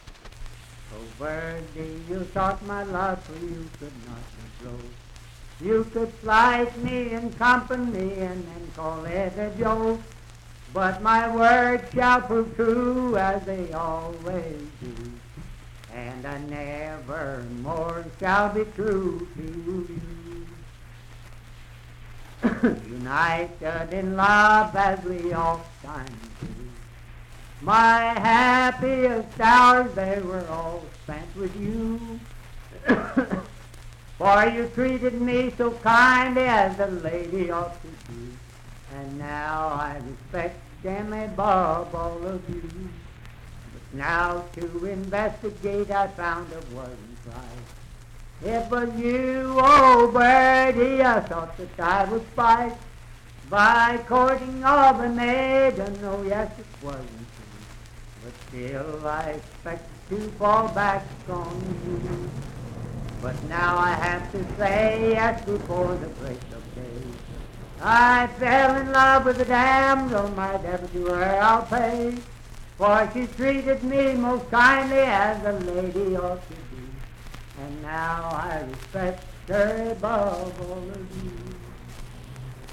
Unaccompanied vocal music and folktales
Verse-refrain 3(4).
Voice (sung)
Parkersburg (W. Va.), Wood County (W. Va.)